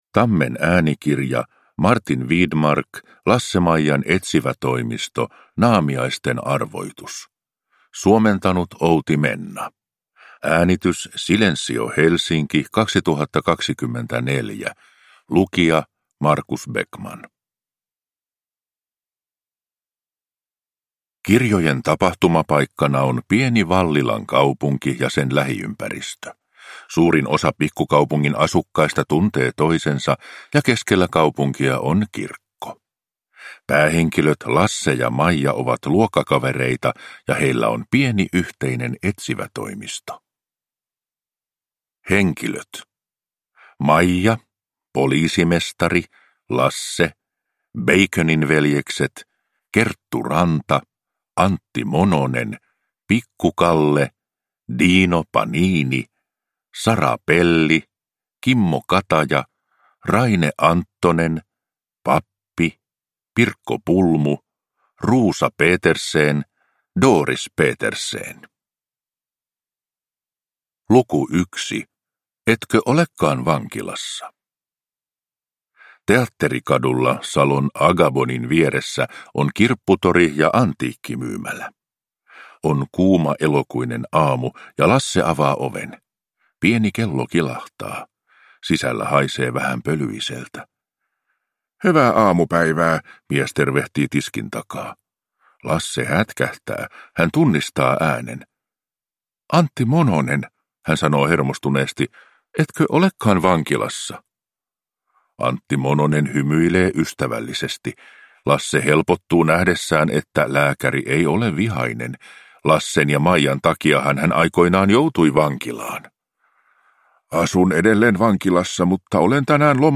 Naamiaisten arvoitus. Lasse-Maijan etsivätoimisto – Ljudbok